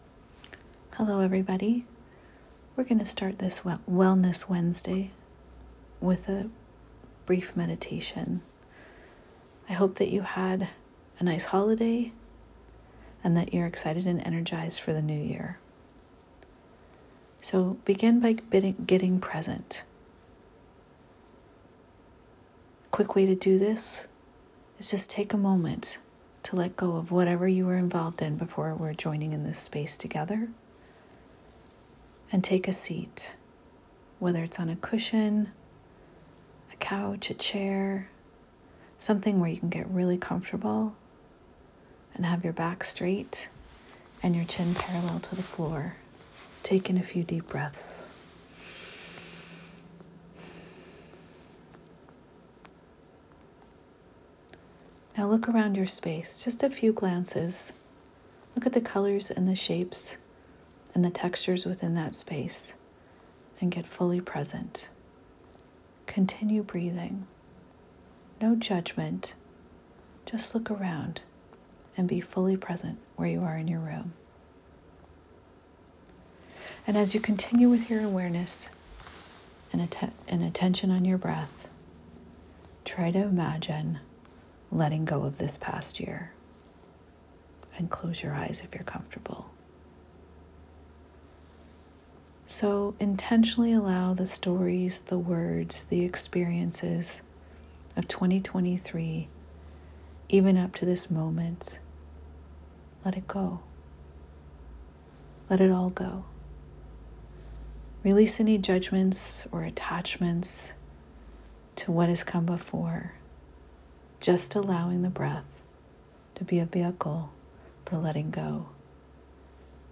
You can read the meditation below and/or listen to my recorded version of the meditation.